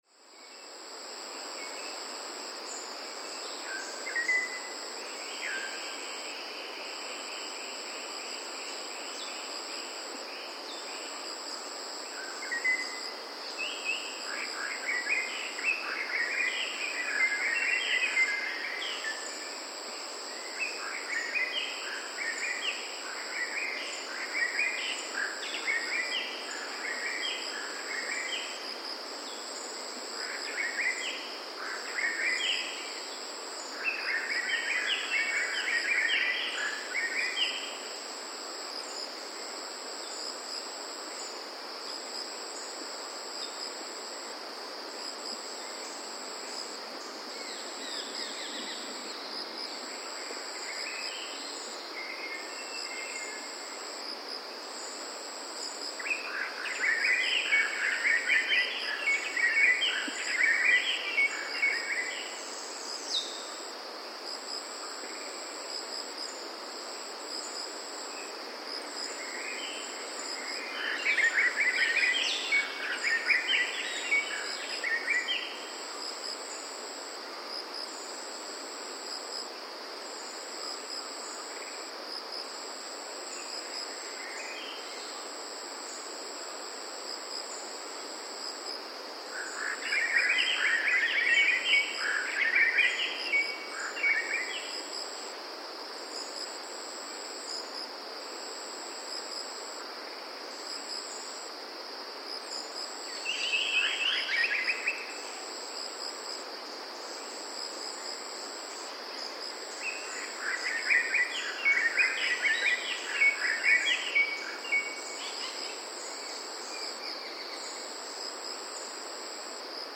Rainforest
It is very rich in the sounds of birds confined to rainforest as well as others distributed throughout wet-zone evergreen forests, and of mammals and insects.
The locations here are mostly free of man-made noises.
Sinharaja-1_Rode-XY.mp3